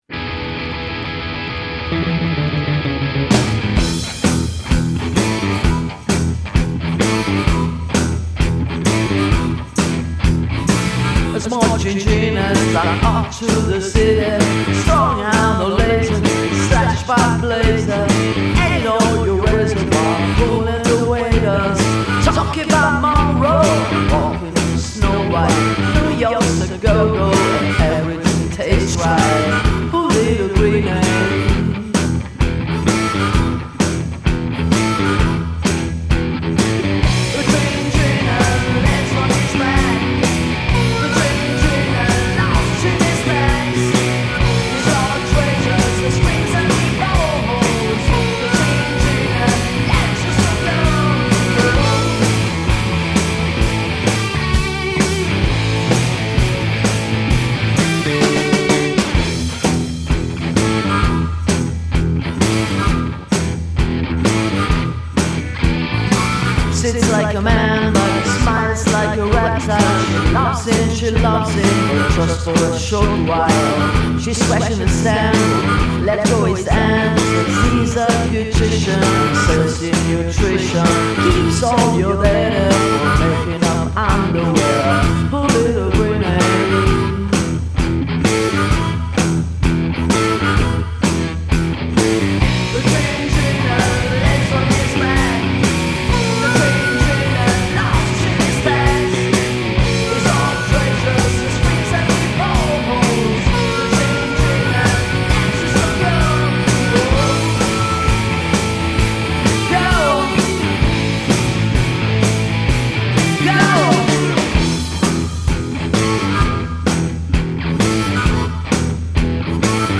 batteria, percussioni
armonica, flauto, sassofono, tastiere